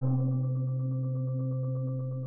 Pad - Smooth.wav